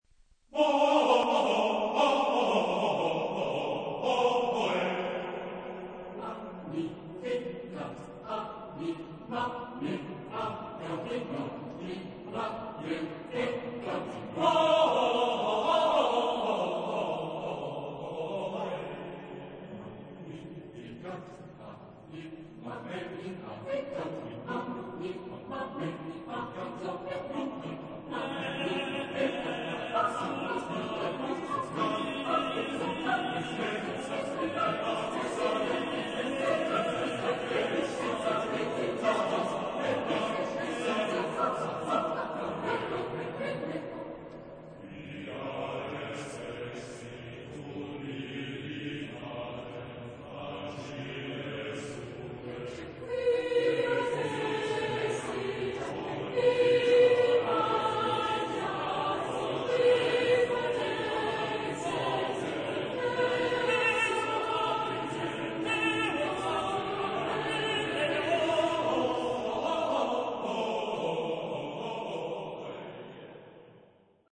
Epoque: 20th century
Type of Choir: SSAATTBB  (8 mixed voices )